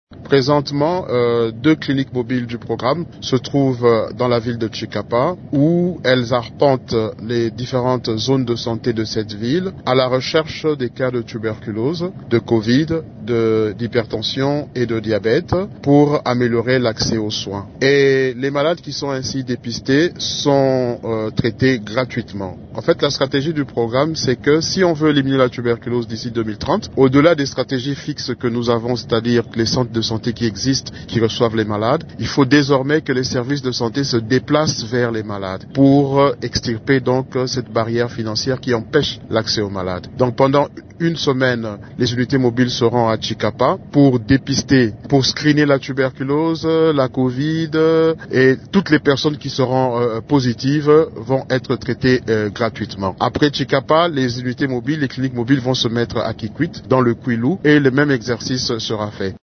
Le docteur Michel Kaswa Kayomo, directeur du PNLT, indique que les deux Cliniques mobiles qui font cette caravane sont dotées chacune, notamment, d’équipements de diagnostic de la tuberculose :